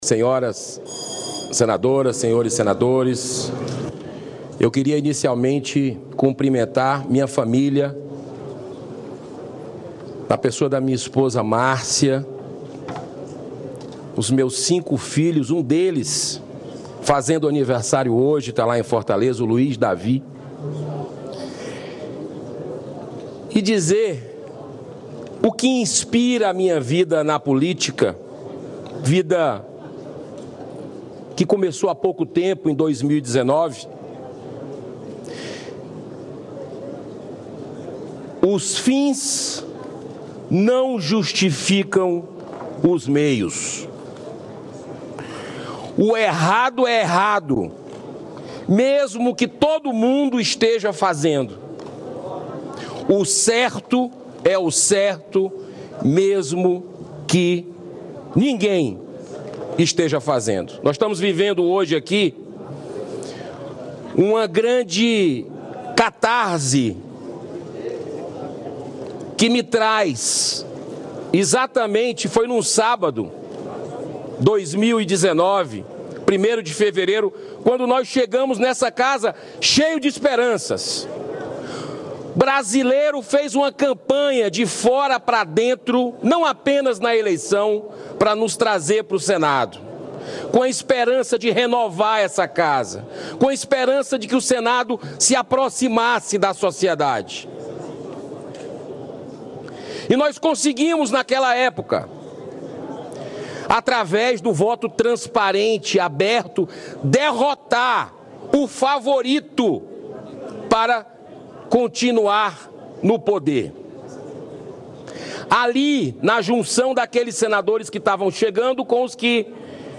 Discurso do candidato Eduardo Girão
O senador Eduardo Girão (Novo-CE) apresenta suas propostas para presidir o Senado em discurso na reunião preparatória deste sábado (1º).